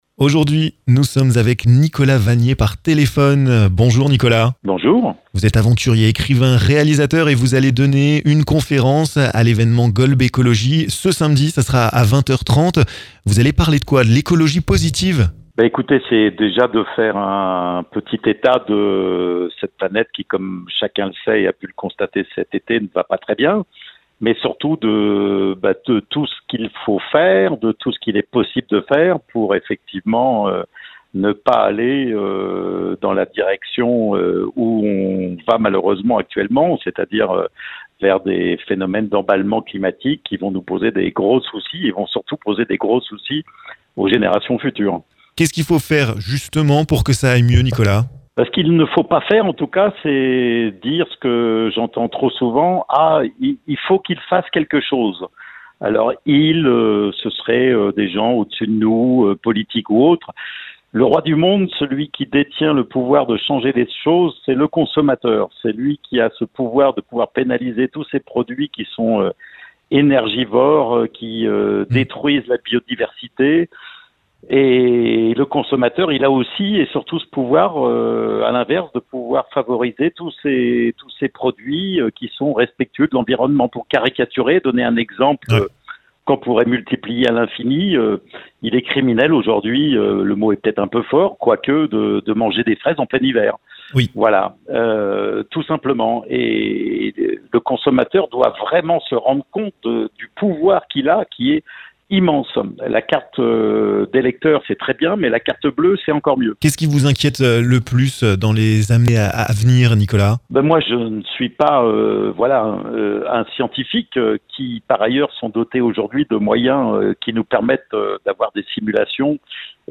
Le célèbre aventurier, réalisateur et écrivain a accpté de répondre aux questions de Vosges FM. Il tire la sonette d'alarme sur l'environnement et nos mauvaises habitudes.